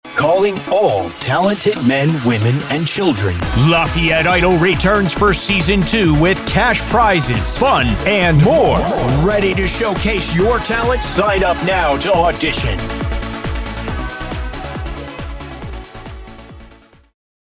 Edge of your seat web ad for local talent contest!